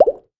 Double_bubble_hit_1.wav